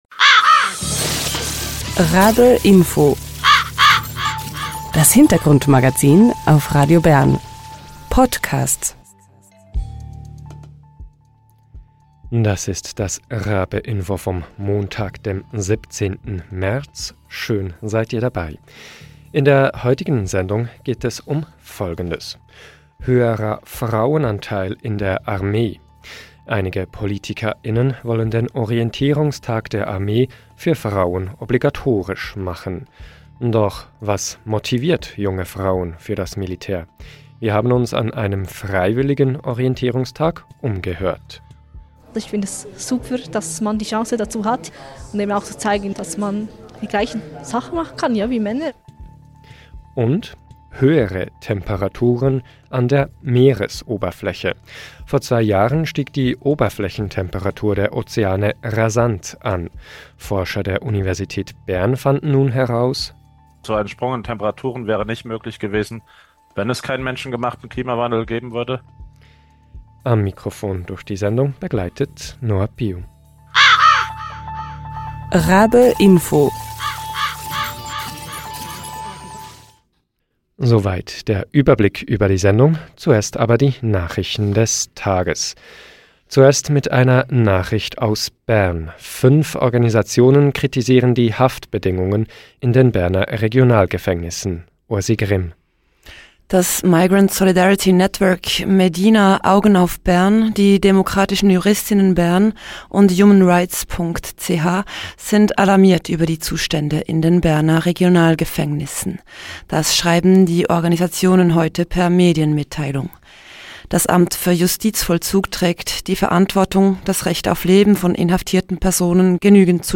Wir haben uns an einem freiwilligen Orientierungstag der Schweizer Armee umgehört.
Wir sprechen mit einem Physiker der Universität Bern über seine Forschungsergebnisse.